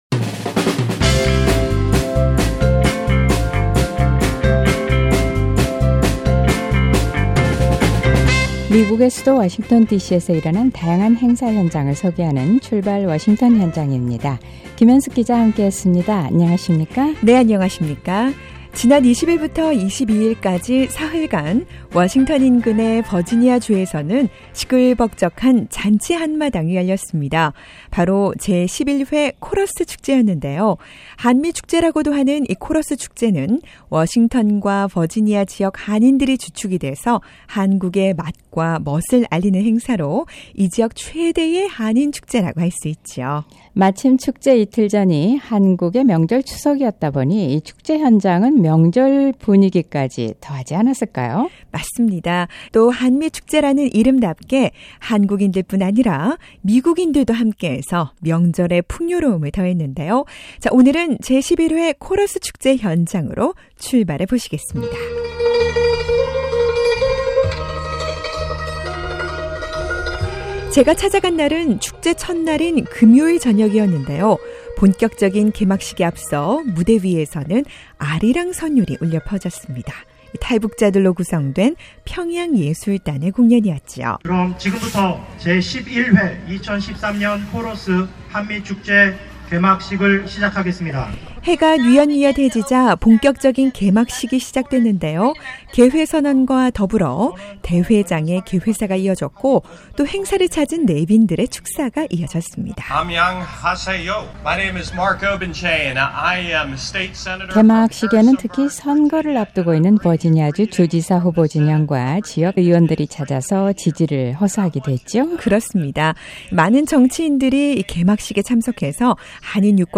워싱턴 지역의 최대의 한인 축제, 제11회 코러스 축제가 성공리에 마무리됐습니다. 시끌벅적한 장터 같은 분위기에, 다양한 공연들로 한류의 열기까지 느낄 수 있었던 코러스 축제.
오늘은 뜨거운 열기와 흥겨움이 넘쳤던 코러스 축제 현장으로 출발해 봅니다.